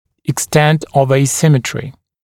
[ɪk’stent əv eɪ’sɪmətrɪ] [ek-][ик’стэнт ов эй’симэтри] [эк-]степень асимметрии